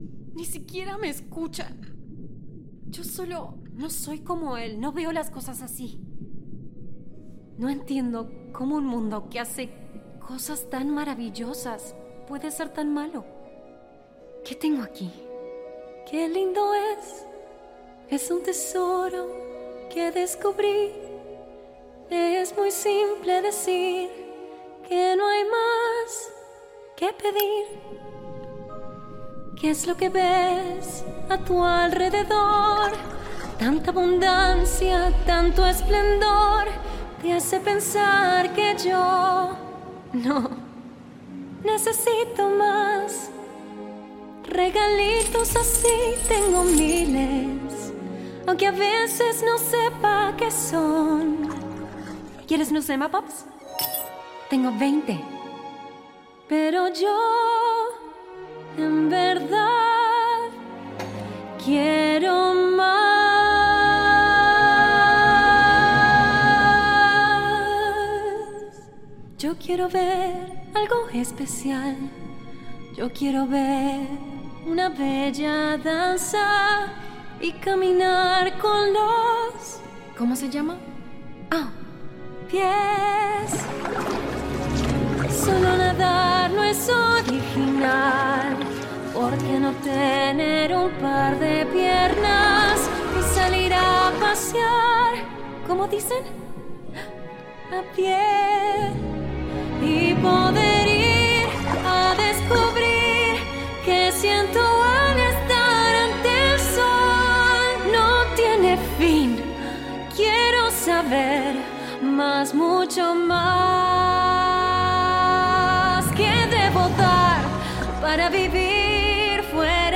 Singing dubbing demo (LATAM SPANISH)